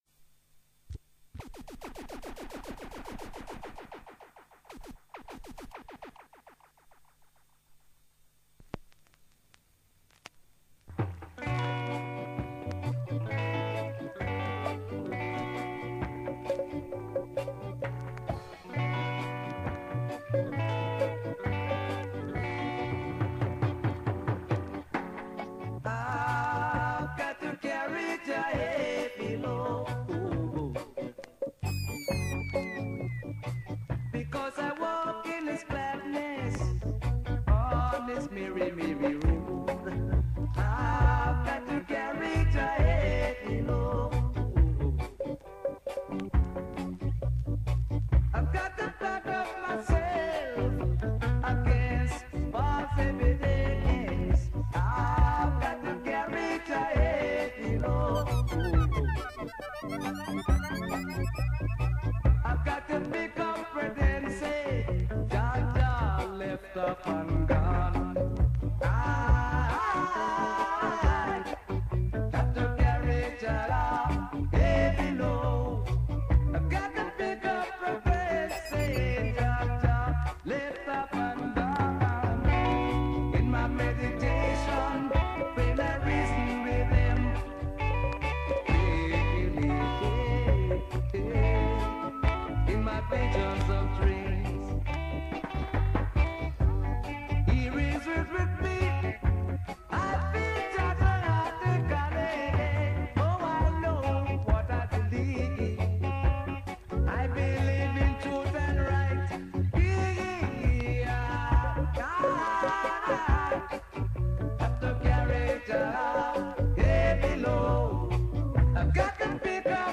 Strictly Vinyl Selection